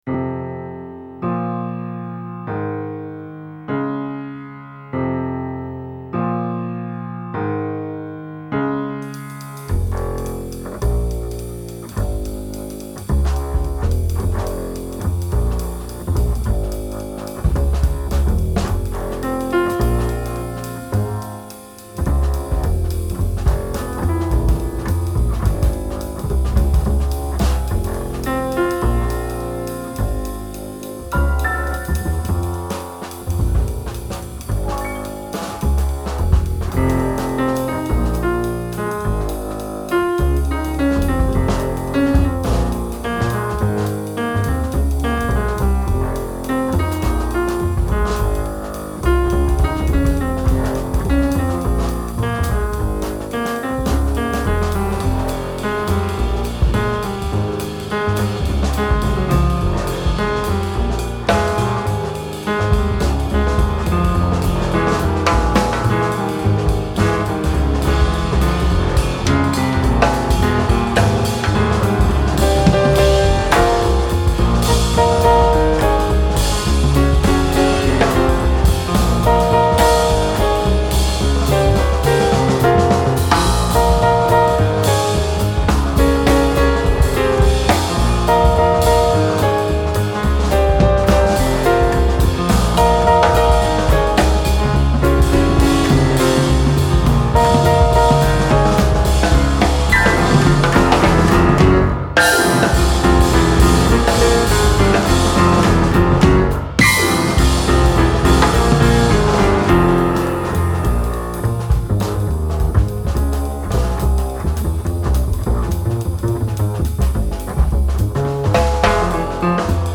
jazznummer